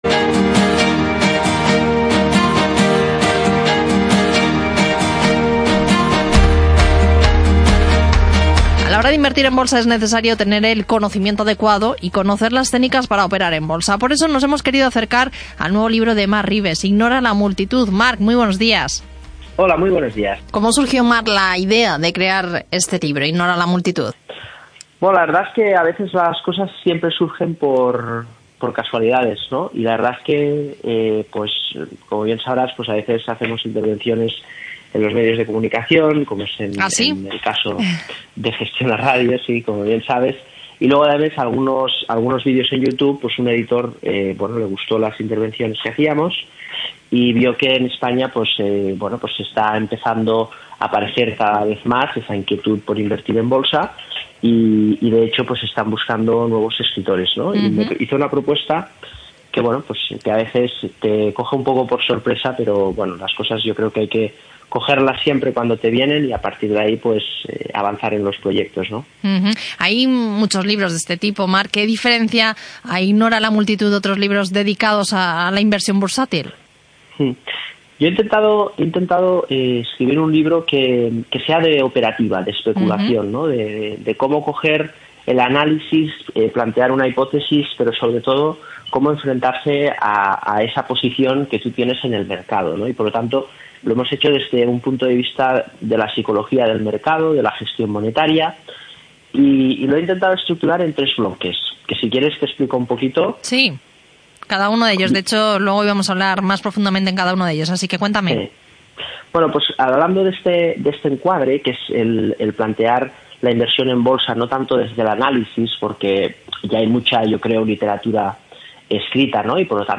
Entrevista en Gestiona Radio, sobre Ignora la Multitud